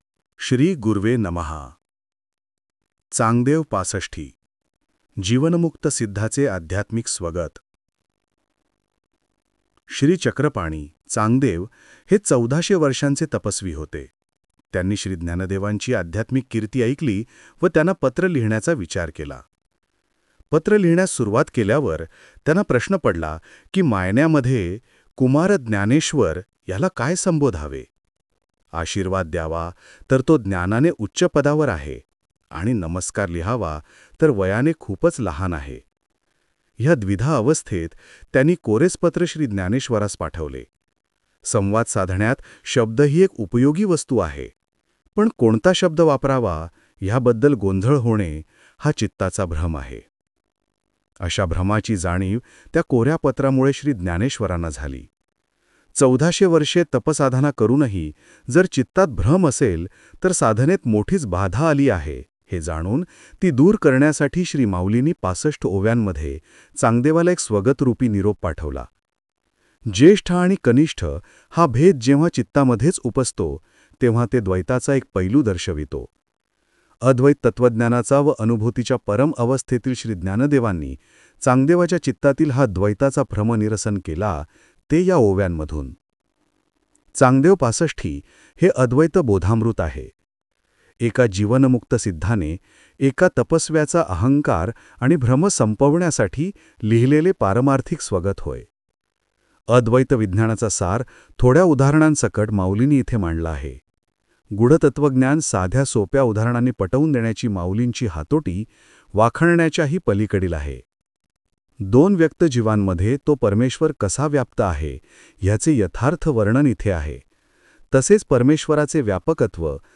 • स्वरुप: डिजिटल ऑडिओ डाउनलोड / स्ट्रीम (Audiobook)